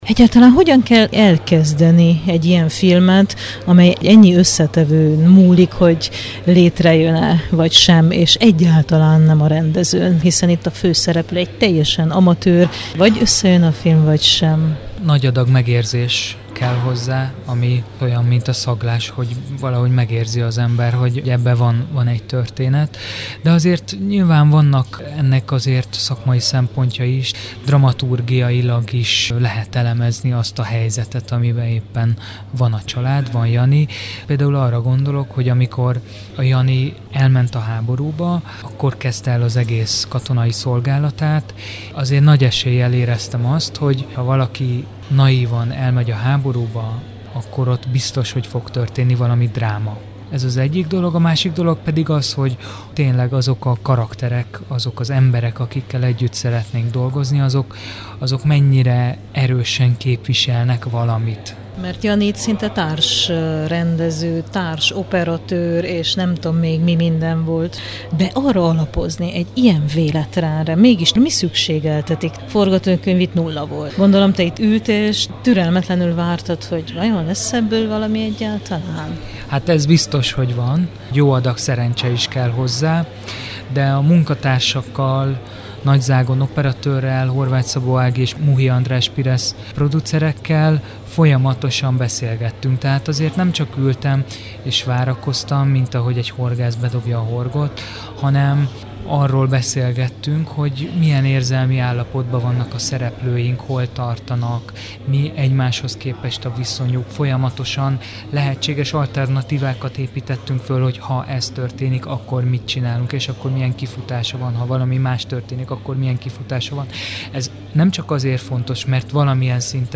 beszélgetett a film után